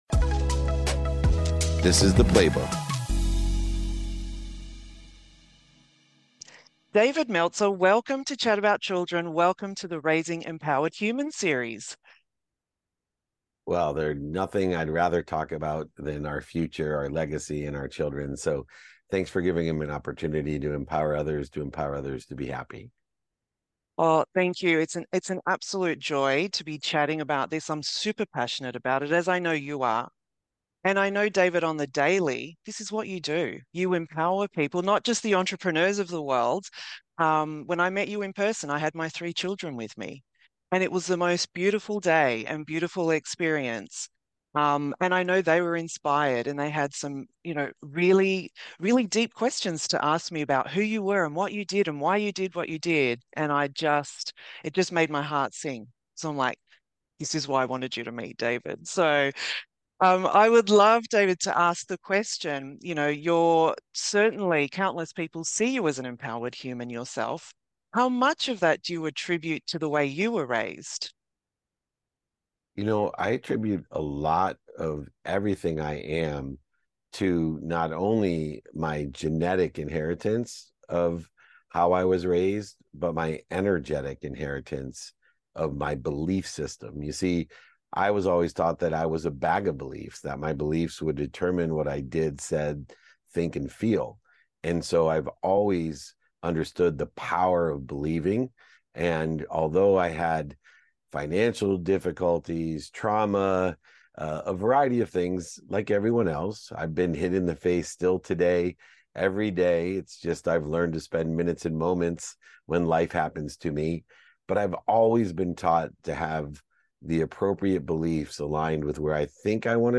Today's episode is from a conversation